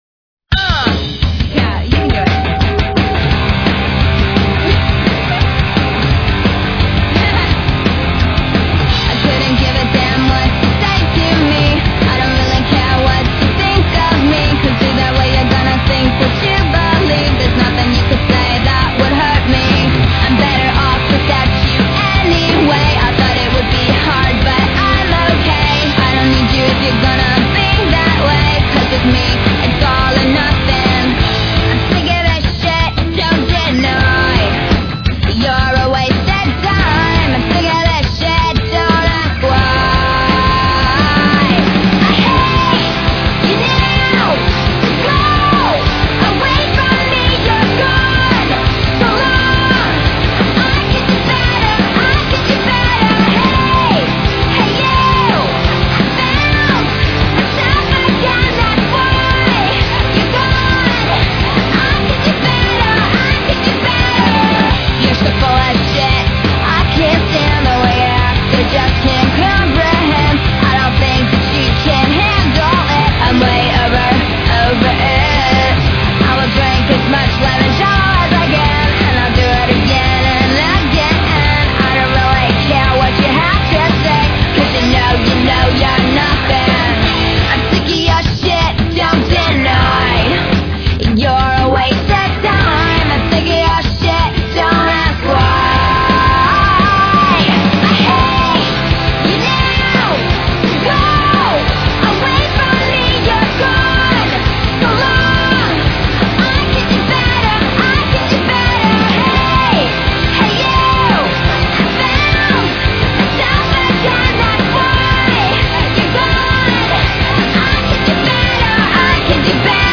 Tags: punk